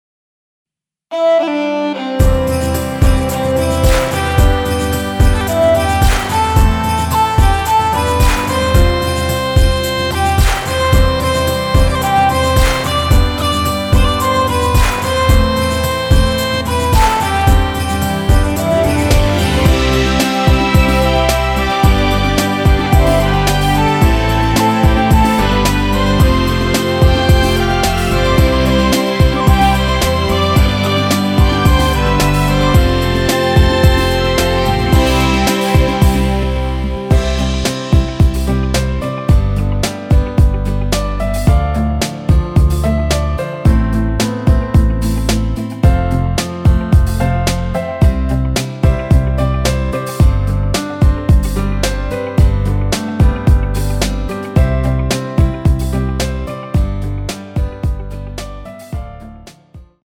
원곡에서 4분 45초까지하고 엔딩을만들어 놓았습니다.(멜로디 MR 미리듣기 확인)
원키에서(-2)내린 MR입니다.
앞부분30초, 뒷부분30초씩 편집해서 올려 드리고 있습니다.
중간에 음이 끈어지고 다시 나오는 이유는